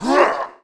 attack_2.wav